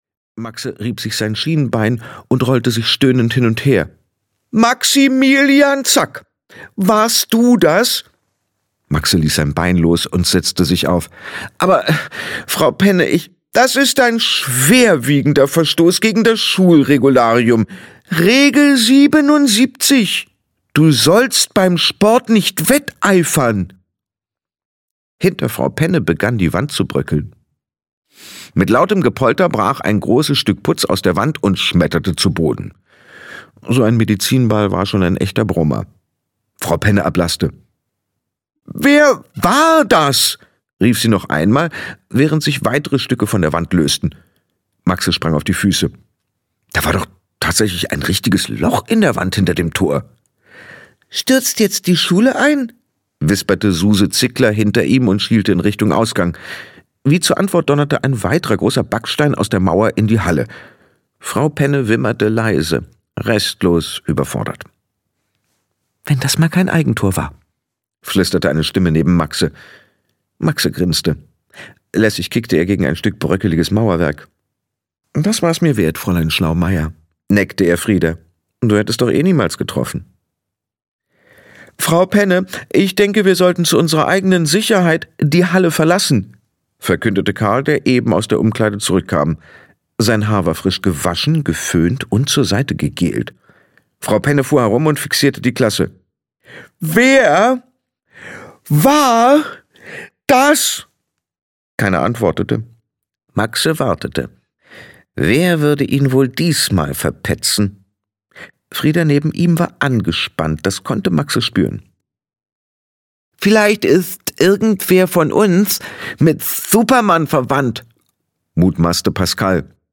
Die unlangweiligste Schule der Welt 2: Das geheime Klassenzimmer - Sabrina J. Kirschner - Hörbuch